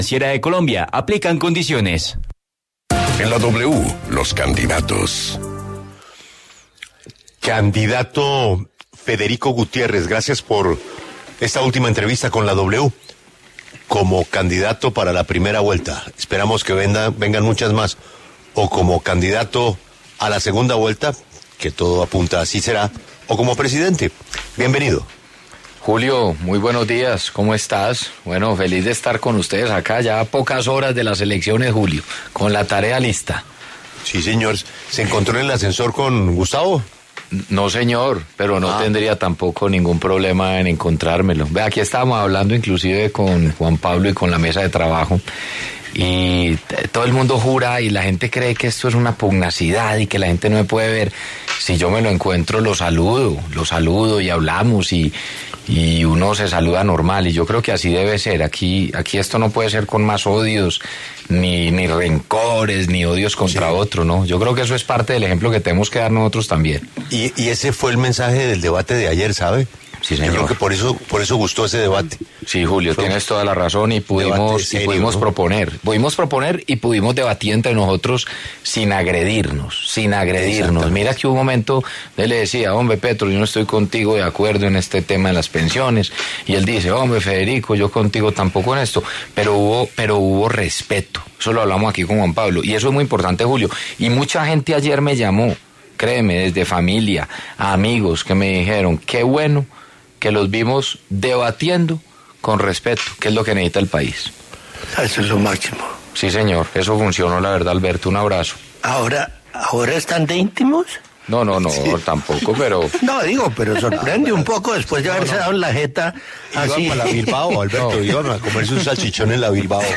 En diálogo con La W, el candidato presidencial Federico ‘Fico’ Gutiérrez habló sobre su campaña, las denuncias contra el Pacto Histórico, los señalamientos del alcalde Daniel Quintero y otros temas.